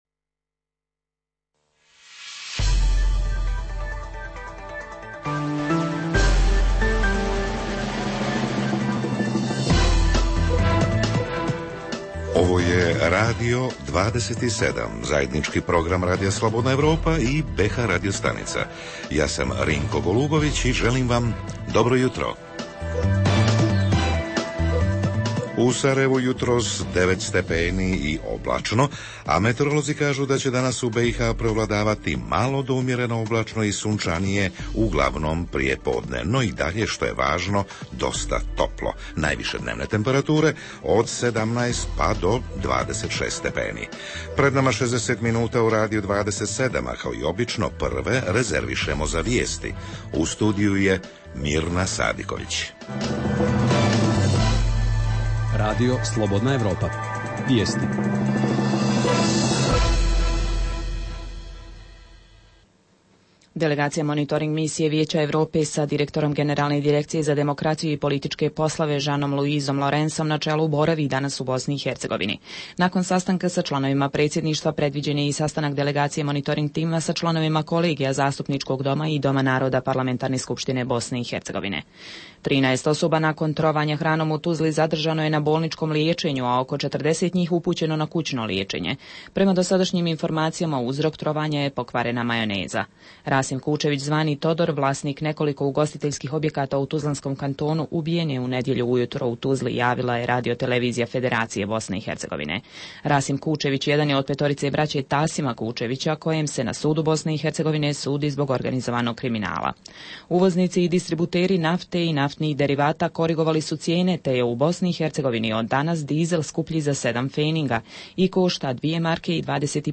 Jutarnji program za BiH koji se emituje uživo. Sadrži informacije, teme i analize o dešavanjima u BiH i regionu, a reporteri iz cijele BiH javljaju o događajima u njihovim sredinama te o najaktuelnijem proteklog vikenda.
Redovni sadržaji jutarnjeg programa za BiH su i vijesti i muzika.